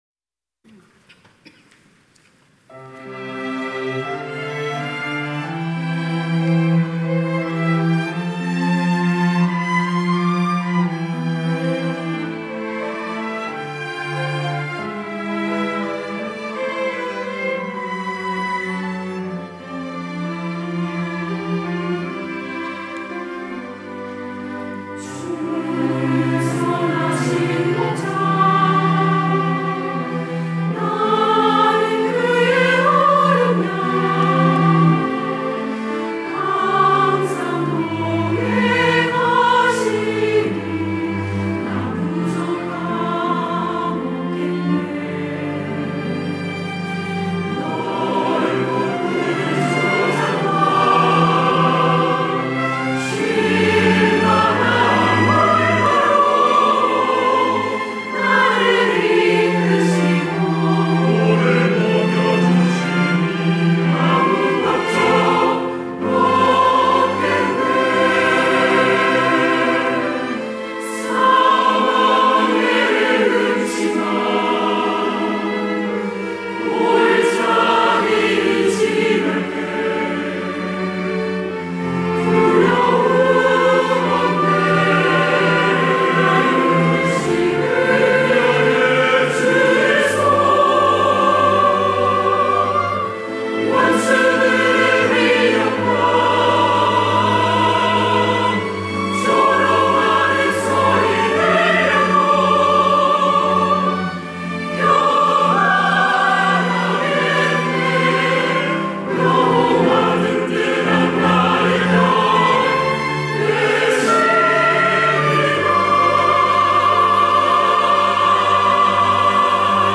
할렐루야(주일2부) - 주는 선하신 목자
찬양대